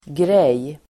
Uttal: [grej:]